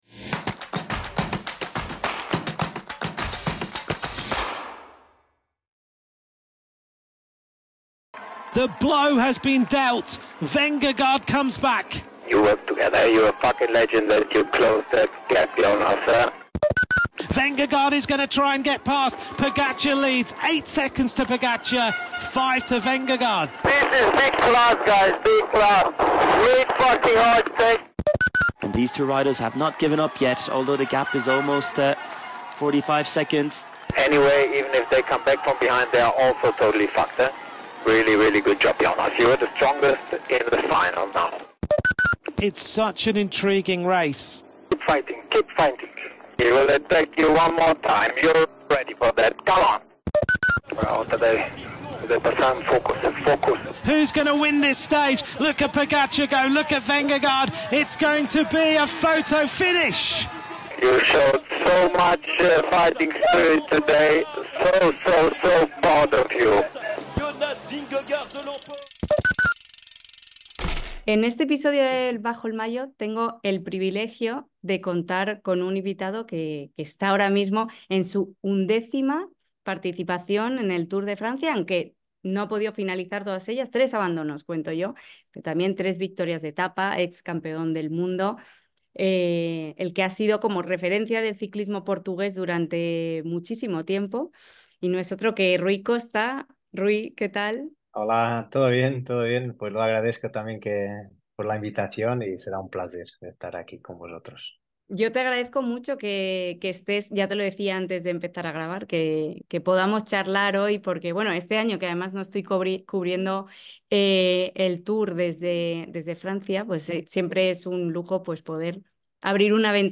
En este episodio charlo con Rui Costa en el primer día de descanso del Tour de Francia sobre una carrera de oportunidades, ilusiones, historias y esperanza.